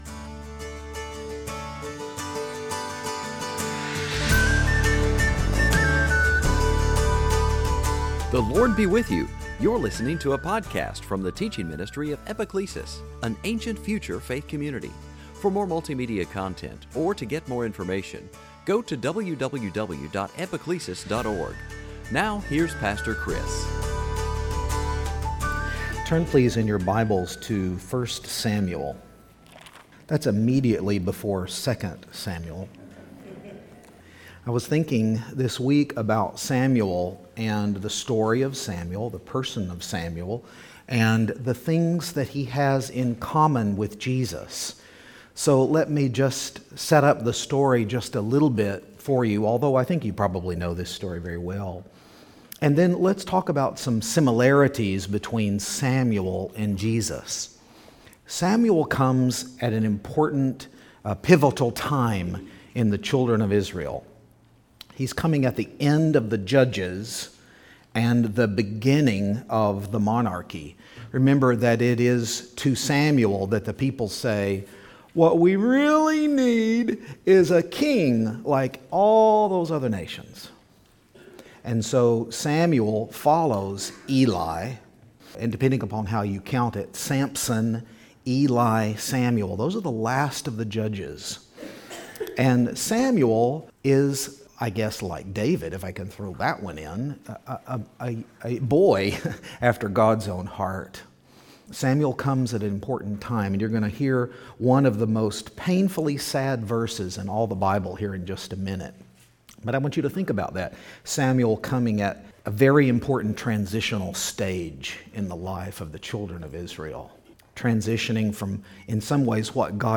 Series: Sunday Teaching Can you imagine being a small boy and hearing from the Lord in the middle of